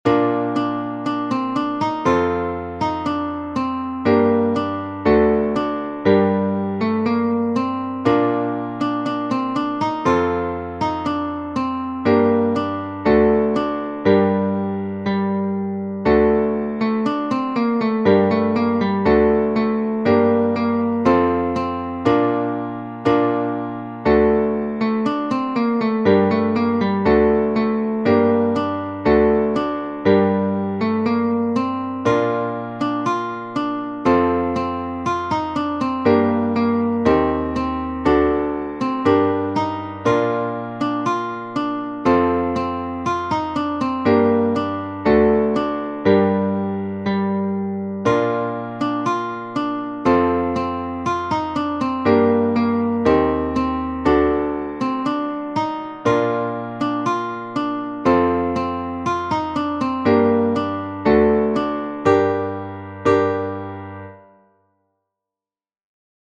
Ukrainian National Anthem for guitar with chord shapes and tabs for the melody.
MP3 file below plays a midi file of the score.